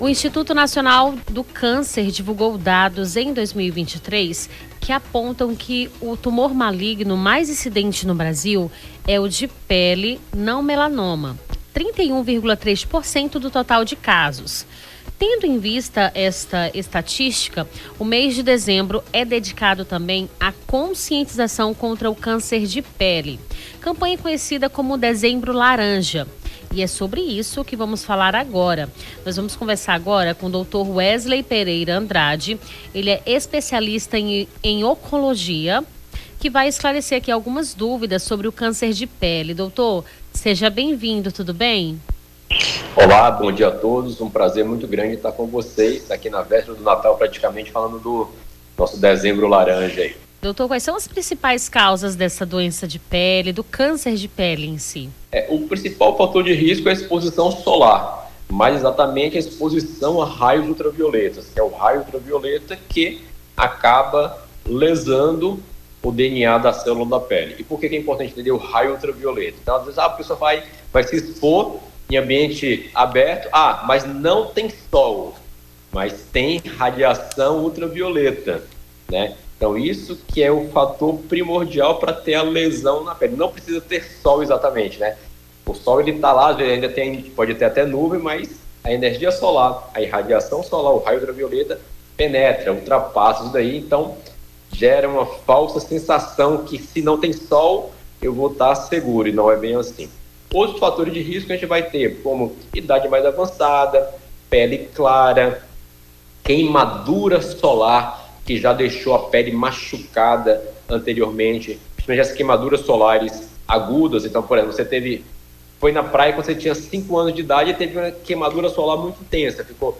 Nome do Artista - CENSURA - ENTREVISTA DEZEMBRO LARANJA (23-12-24).mp3